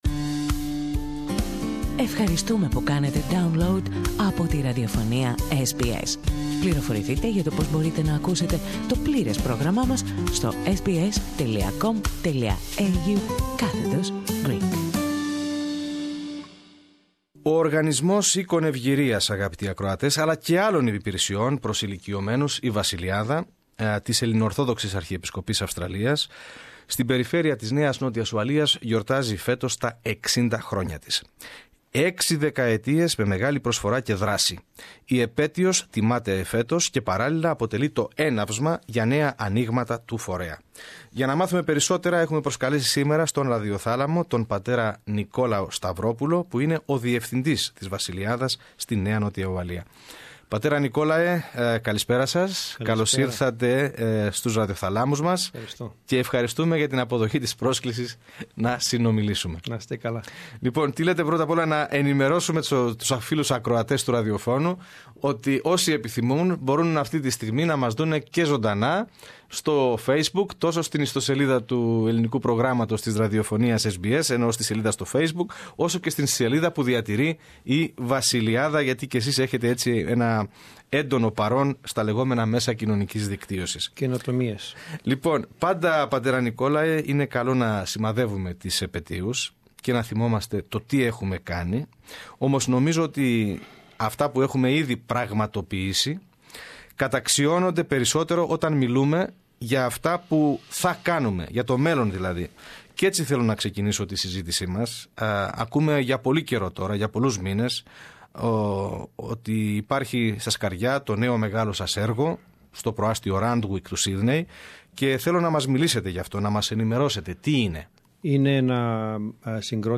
Περισσότερα ακούμε στην συνέντευξη